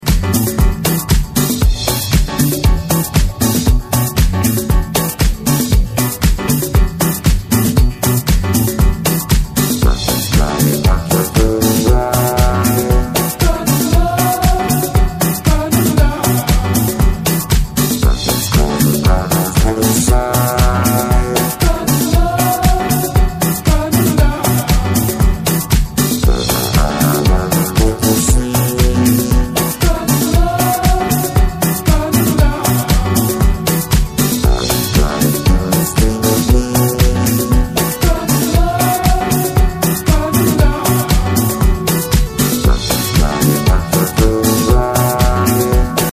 supplier of essential dance music
Electro